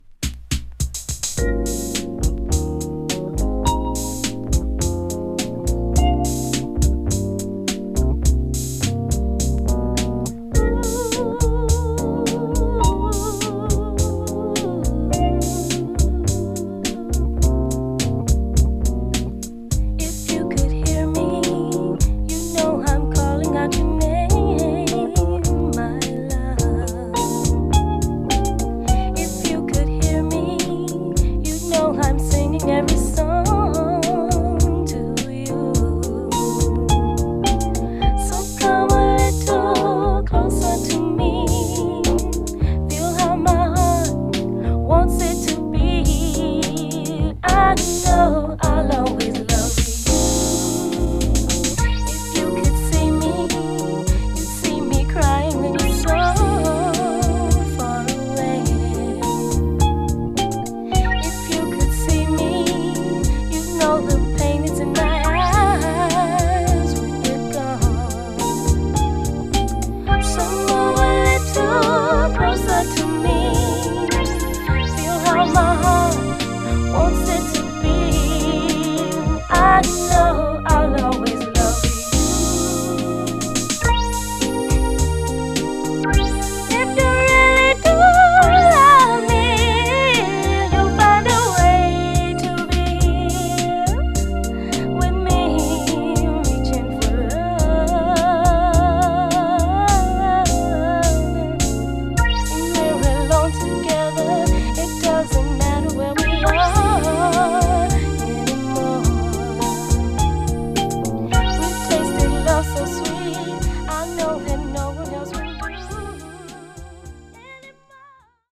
アブストラクトでレフトフィールドなベッドルーム・メロウ・ソウル秘宝プライヴェート・プレス盤85年唯一作!